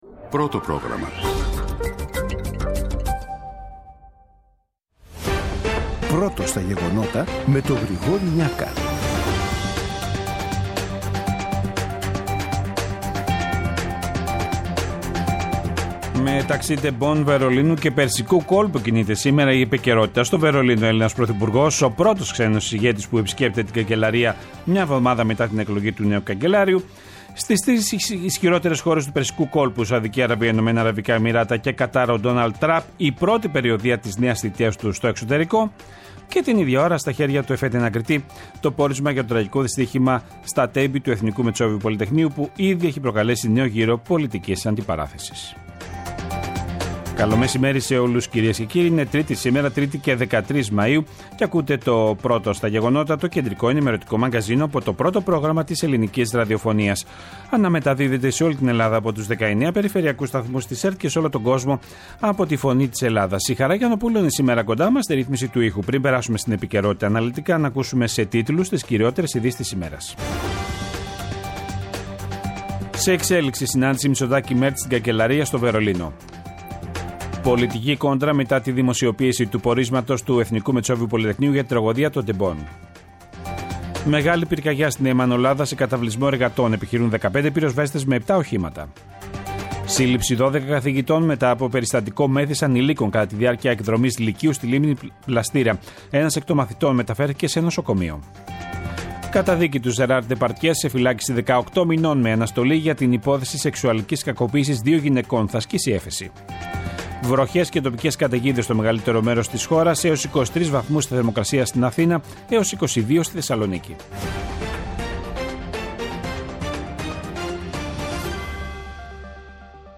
Εκτάκτως σήμερα το μαγκαζίνο εχει μεγαλύτερη διάρκεια γιατι περιλαμβάνει τις δηλώσεις του πρωθυπουργού Κυριάκου Μητσοτάκη και του γερμανού Καγκελαρίου Φρίντριχ Μέρτς σε απευθείας σύνδεση με το Βερολίνο.
Το αναλυτικό ενημερωτικό μαγκαζίνο του Α΄ Προγράμματος, από Δευτέρα έως Παρασκευή στις 14:00. Με το μεγαλύτερο δίκτυο ανταποκριτών σε όλη τη χώρα, αναλυτικά ρεπορτάζ και συνεντεύξεις επικαιρότητας.